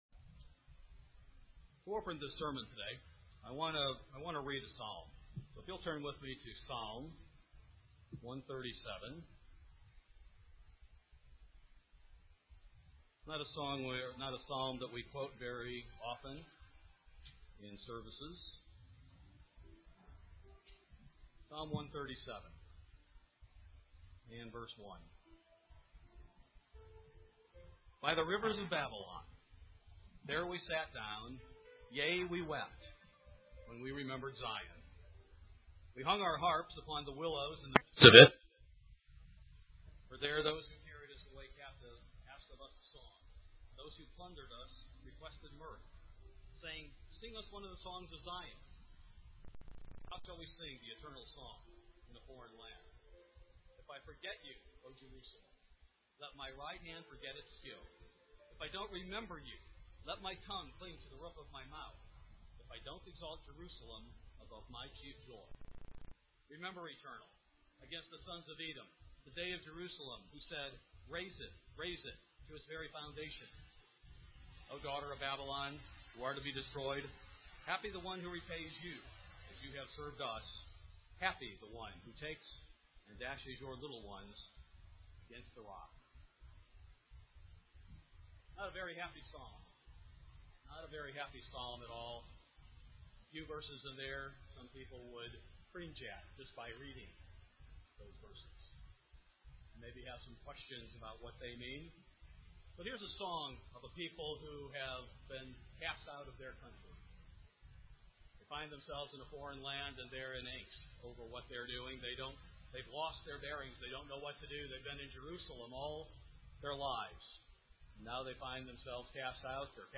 This sermon will provide you with the answers to those questions and more.